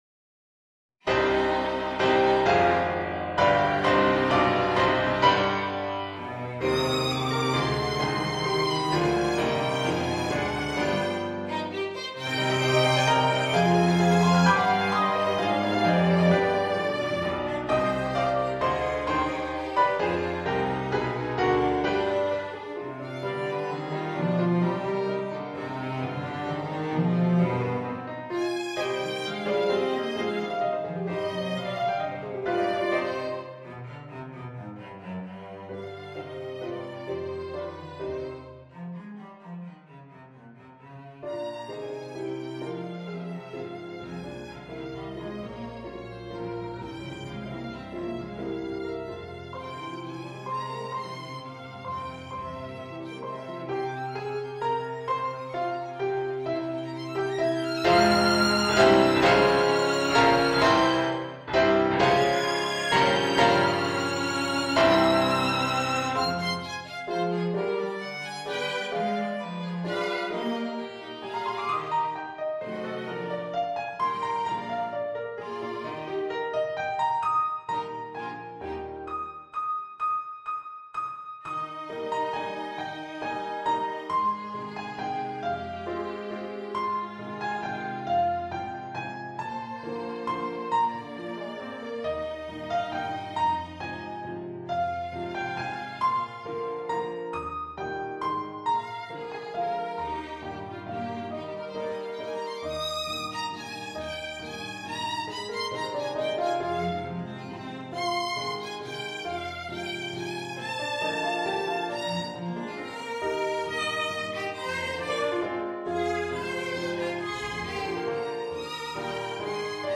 Dear all, and with my glacial pace... here's my next attempt at butchering chamber music! a trio for piano, violin and cello.